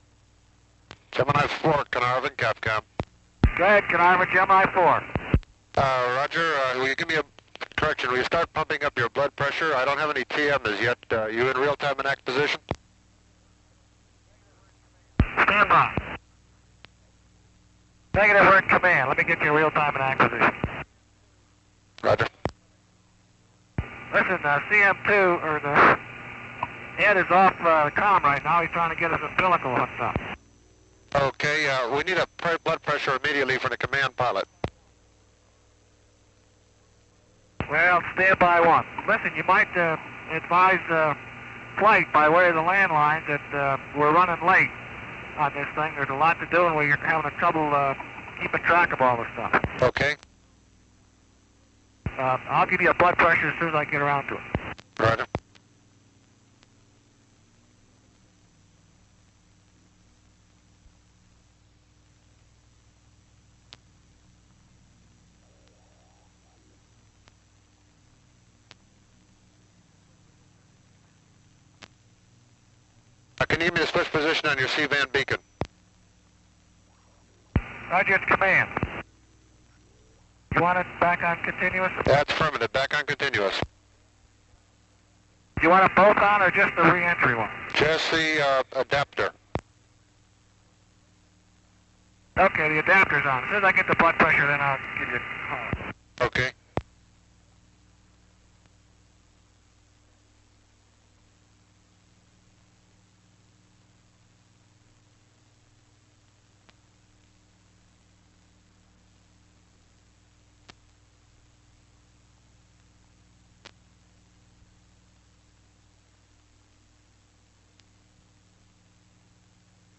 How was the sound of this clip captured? Gemini IV audio as recorded at Carnarvon The audio files of each pass are unaltered, however periods of silence between passes have been removed or reduced.